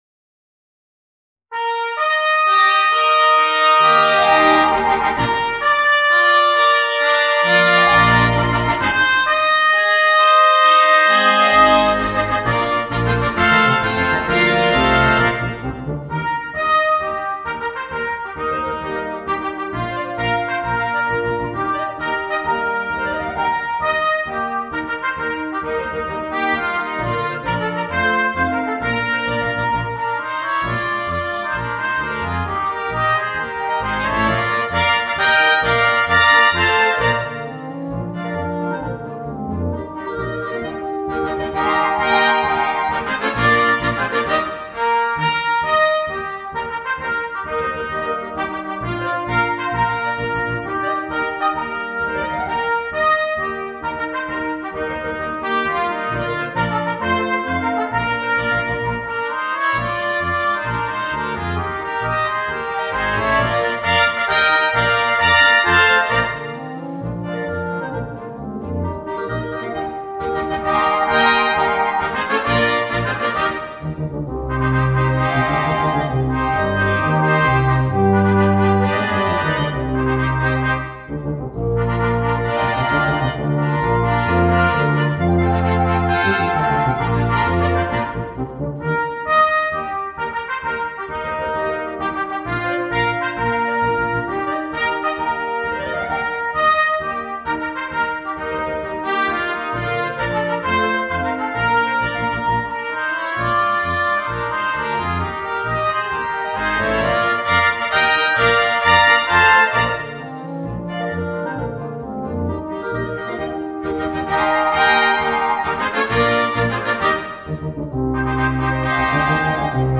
Répertoire pour Brass band - Pop Group et Brass Band